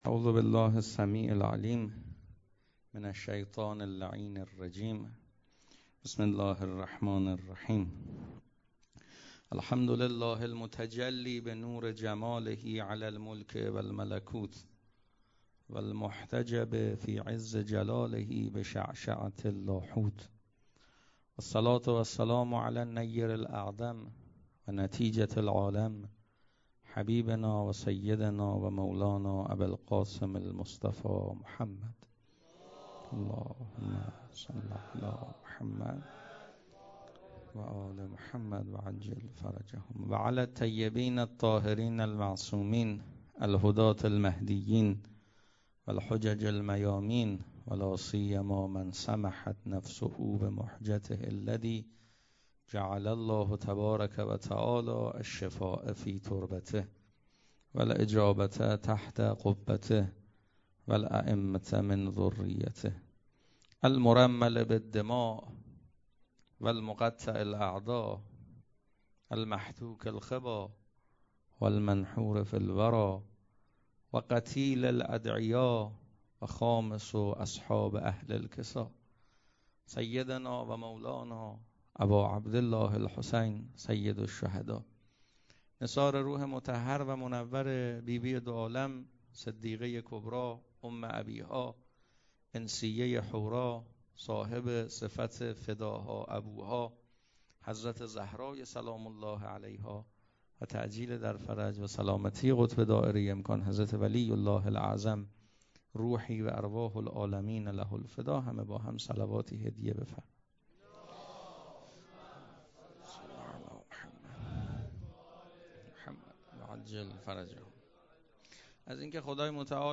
سخنرانی شب اول فاطمیه 99